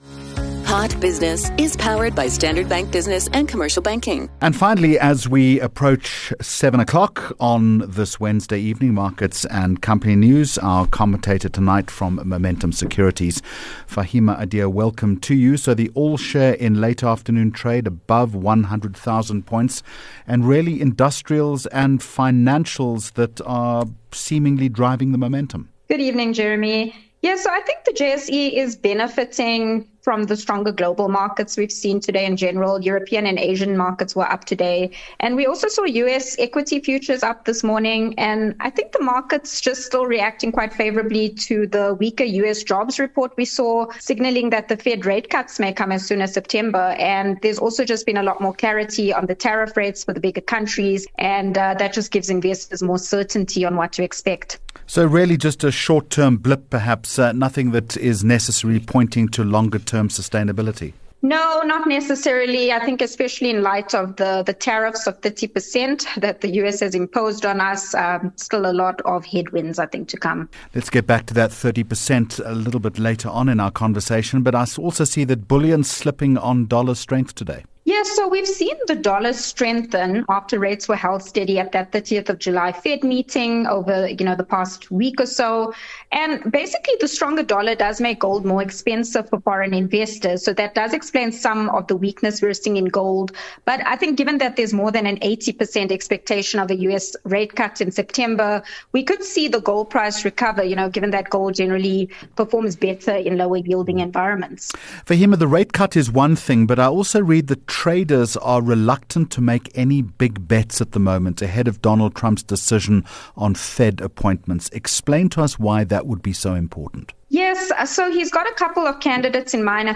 HOT Business with Jeremy Maggs, powered by Standard Bank 6 Aug Hot Business Interview